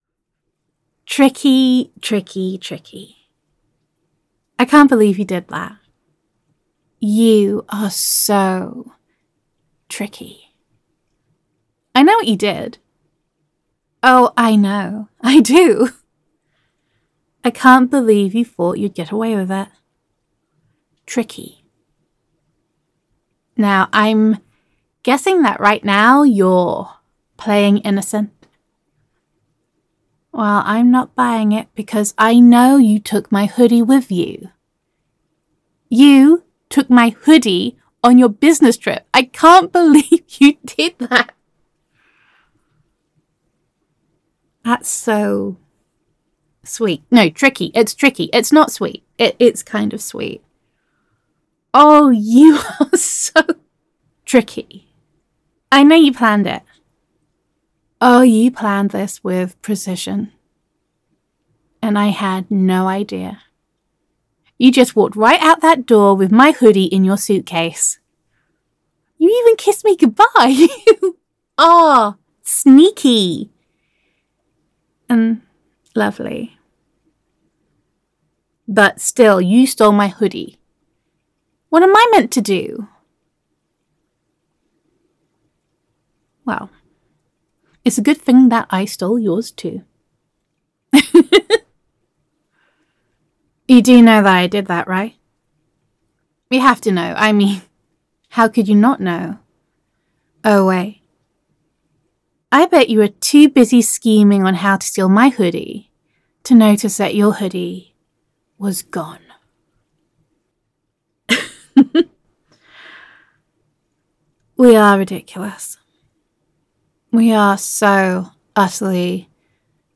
[F4A] Hoodie Hostage [You Are So Tricky][I Know What You Did][Business Trip][Girlfriend Voicemail][Gender Neutral][Did You Really Think You Would Get Away With Stealing My Hoodie?]